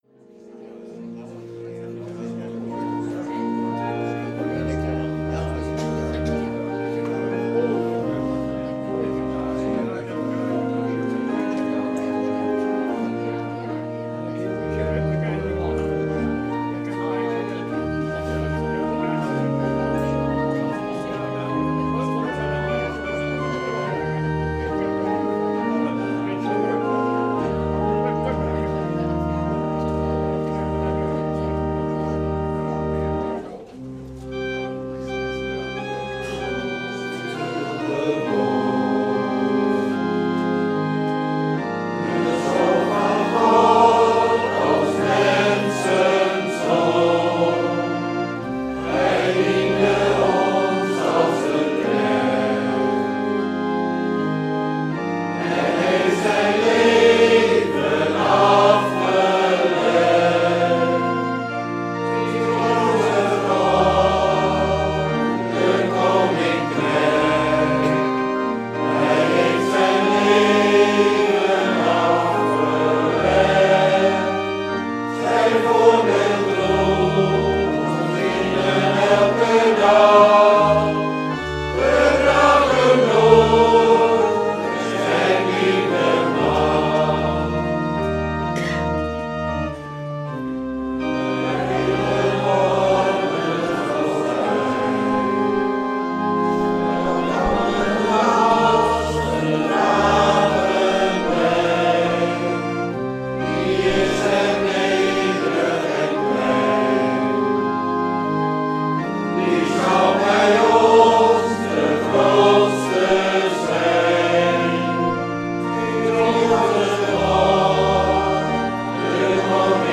Schriftlezing: Johannes 13 : 1 - 15